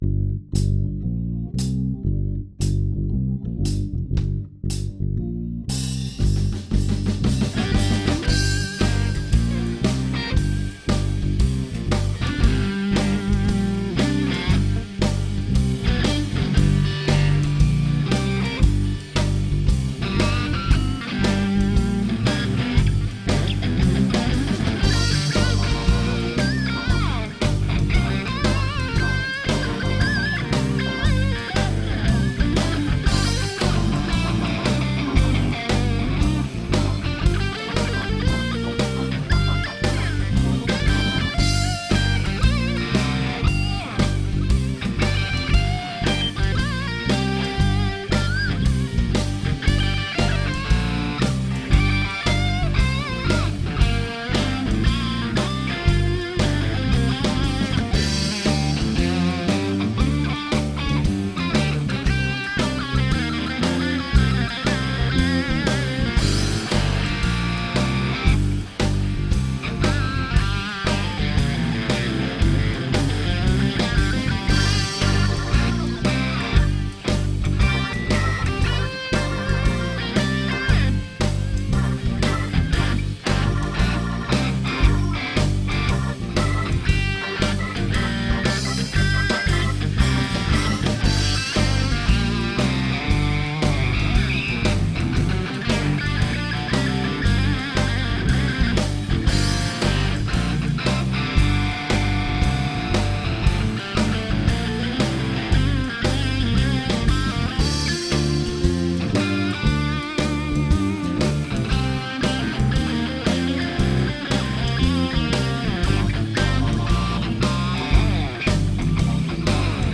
your_browser_is_not_able_to_play_this_audio Ich habe die Version ohne Akkordwechsel genommen, da ich die besser finde.
Sound kommt von der Podfarm am PC in Samplitude 2013, simuliert in dieser Reihenfolge: Distortionpedal -> JMT-45 -> Stereo Chorus -> King Spring